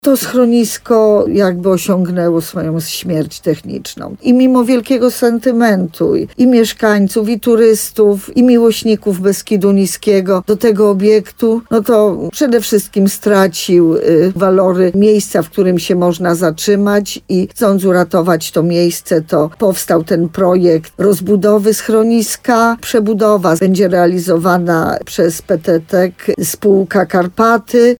Jak powiedziała w programie Słowo za Słowo w programie RDN Nowy Sącz wójt gminy Sękowa Małgorzata Małuch, dawne schronisko było zamknięte od kilku lat, bo nie spełniało już podstawowych norm.